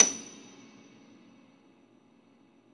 53a-pno28-F6.aif